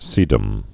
(sēdəm)